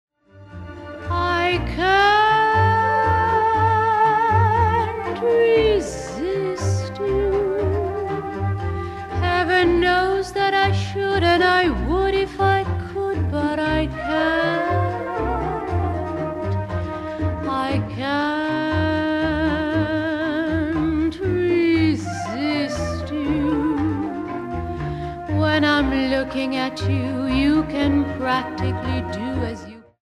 lush and imaginative arrangements for orchestra